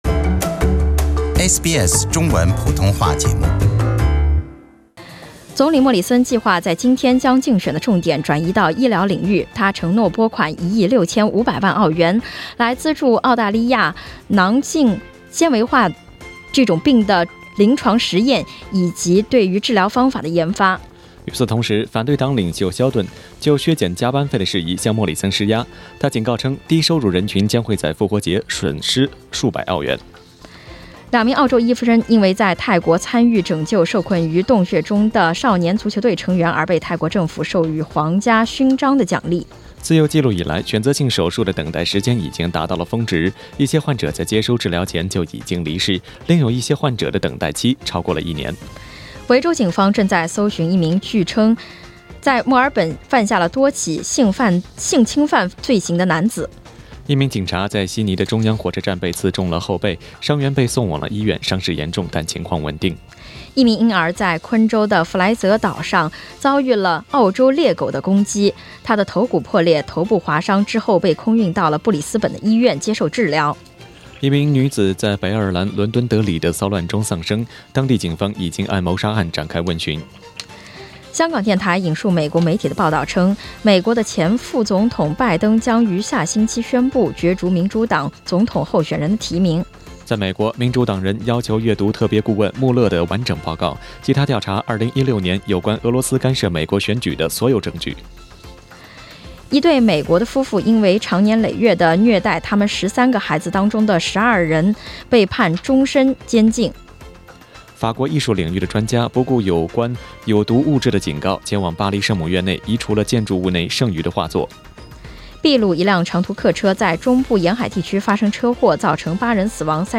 SBS早新闻 (4月20日)